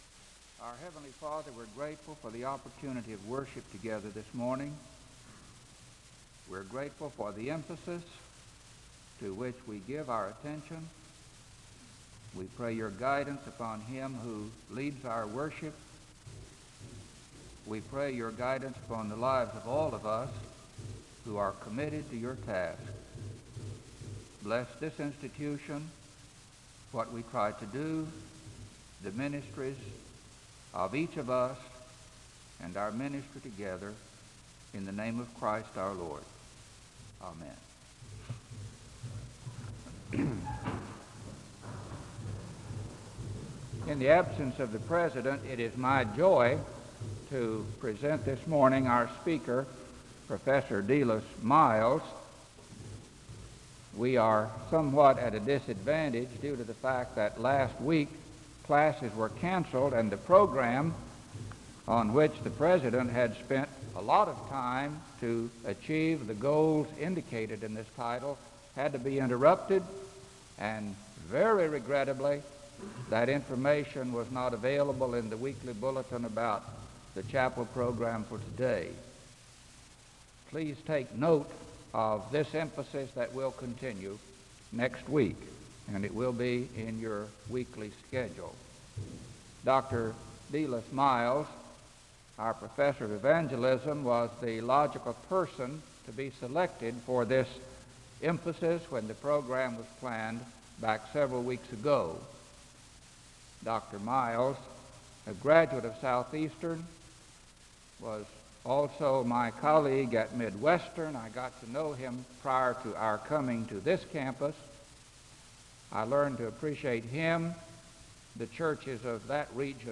The service begins with a word of prayer (00:00-00:40).
The choir sings a song of worship (02:41-06:40).
SEBTS Chapel and Special Event Recordings SEBTS Chapel and Special Event Recordings